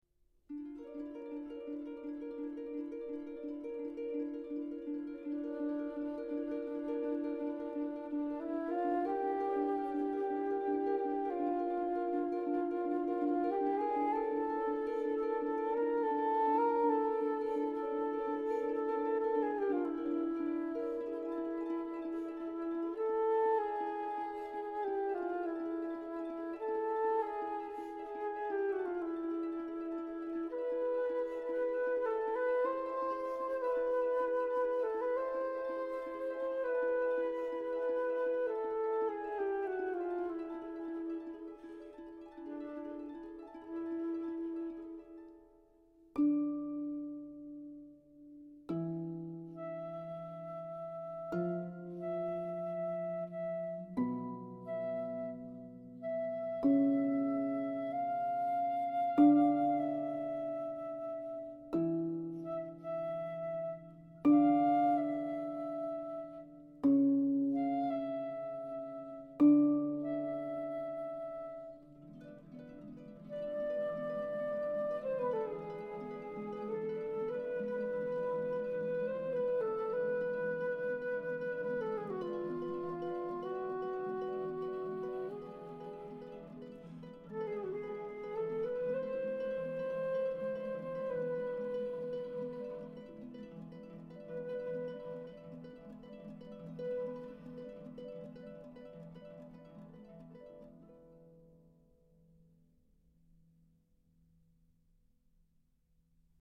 studio recording